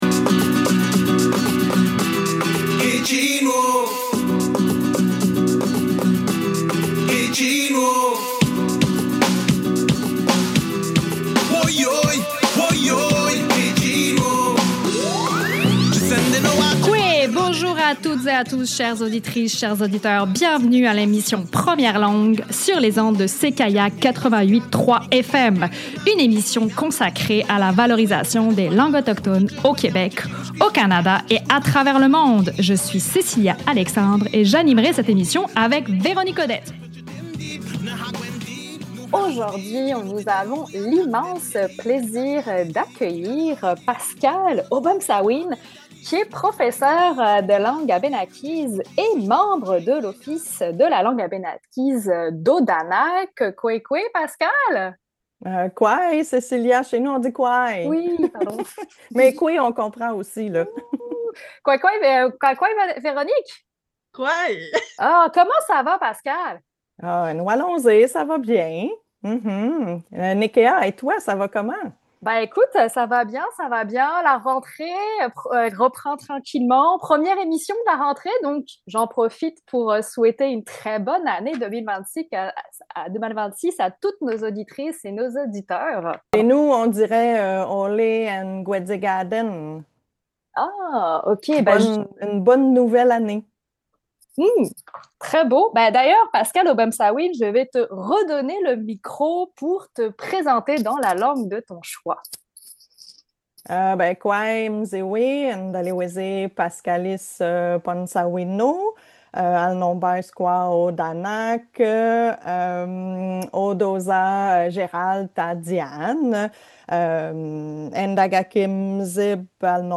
CKIA 88,3 FM - Radio urbaine